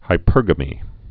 (hī-pûrgə-mē)